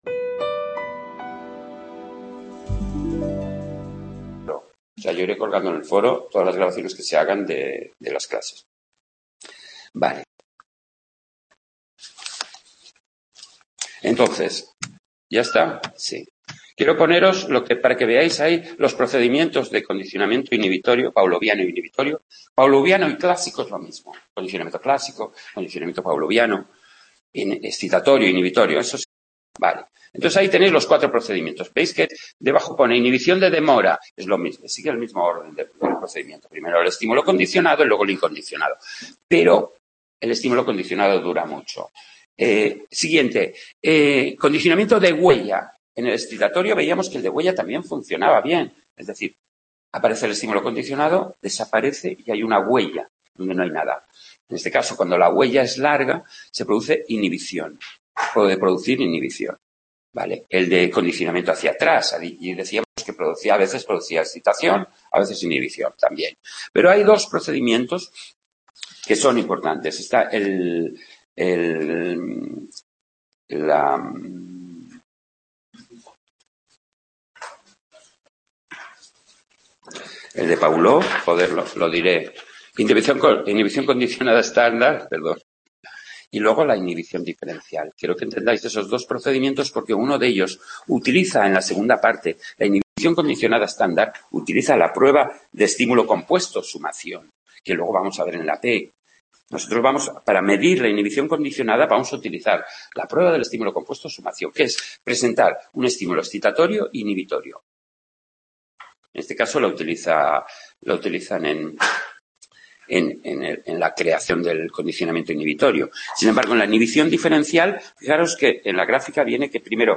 Tema 2 de psicología del Aprendizaje, segunda parte, donde se habla del condicionamiento inhibitorio y de la extinción. Grabado en el aula de Sant Boi